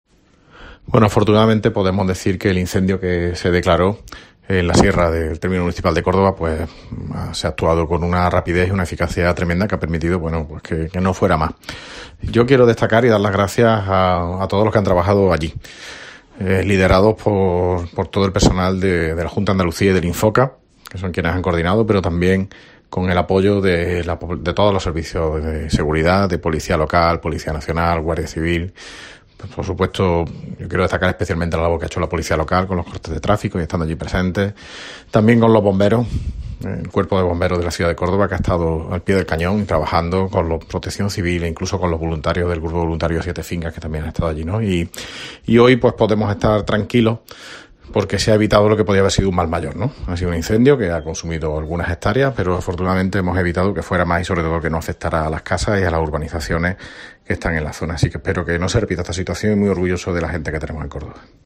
Valoración incendio Alcalde de Córdoba